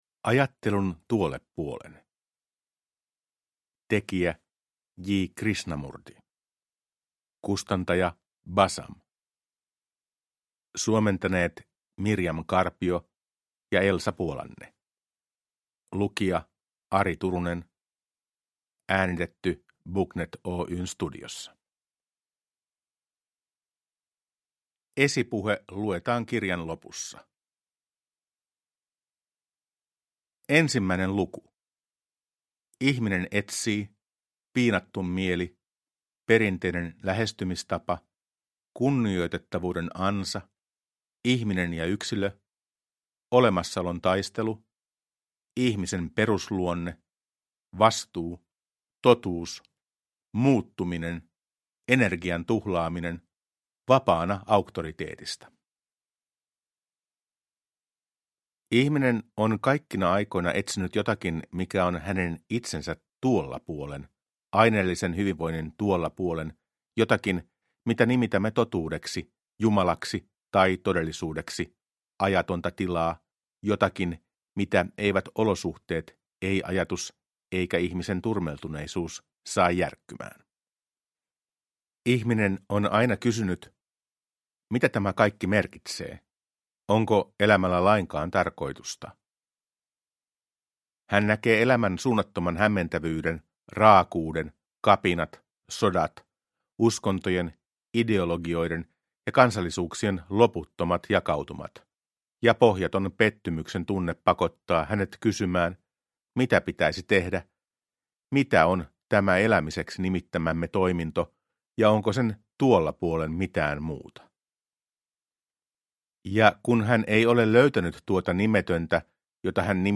Ajattelun tuolle puolen – Ljudbok